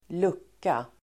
Uttal: [²l'uk:a]